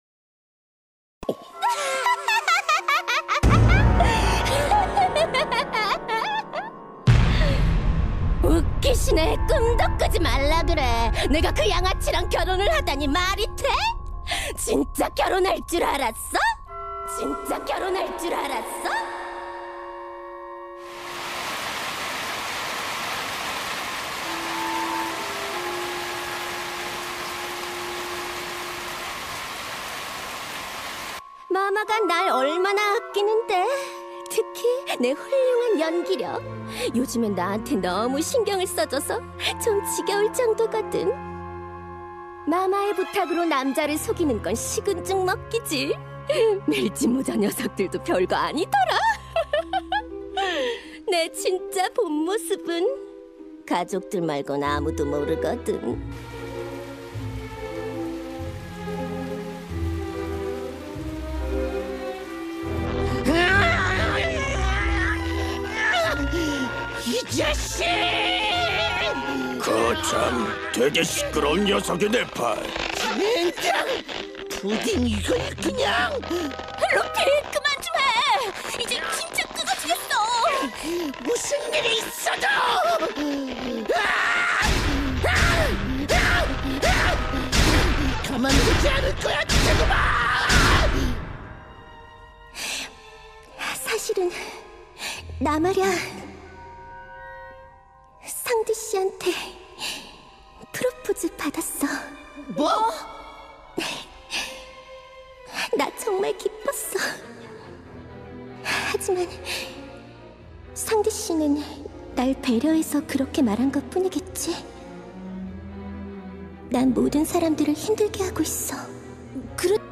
협회성우 | 애드사운드